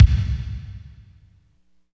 30.10 KICK.wav